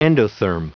Prononciation du mot endotherm en anglais (fichier audio)
Prononciation du mot : endotherm